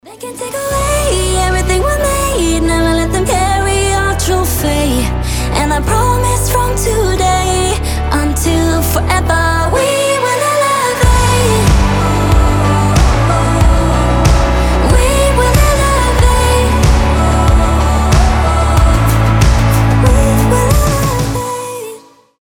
• Качество: 320, Stereo
мелодичные
красивый женский голос